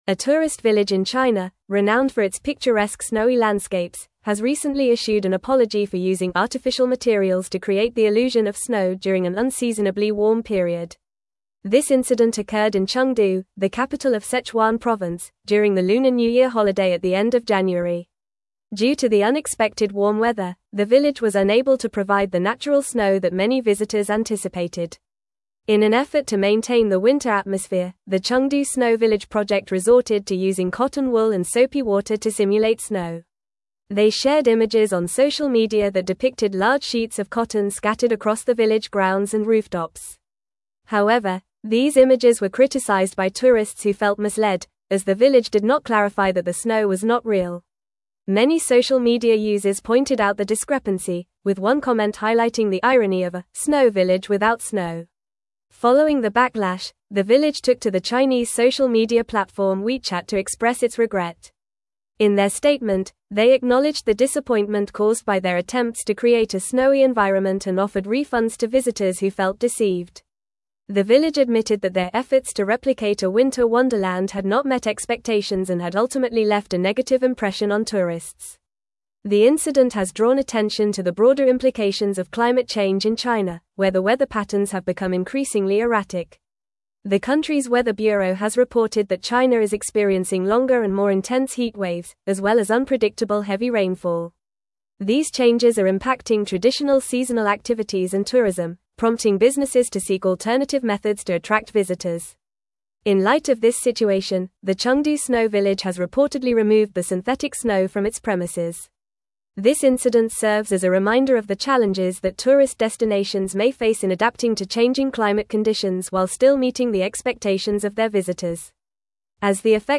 Fast
English-Newsroom-Advanced-FAST-Reading-Chengdu-Village-Apologizes-for-Fake-Snow-Misleading-Tourists.mp3